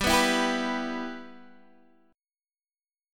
G6 Chord
Listen to G6 strummed